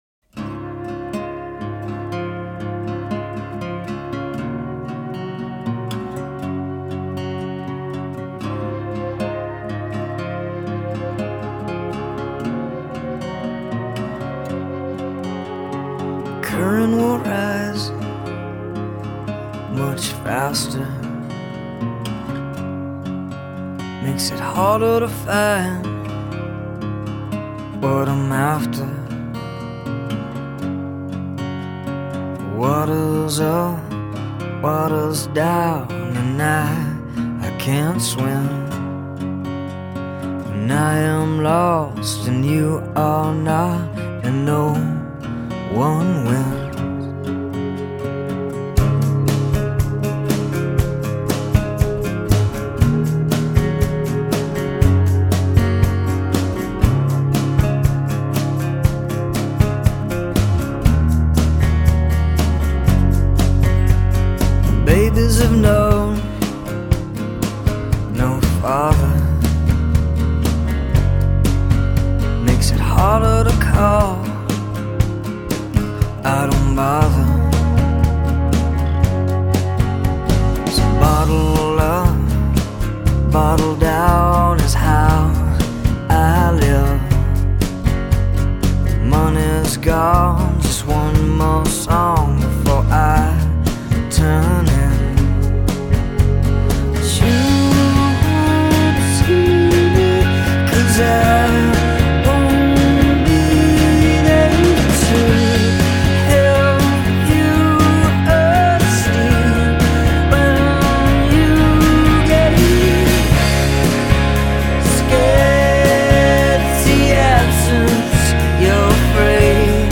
He writes sad songs.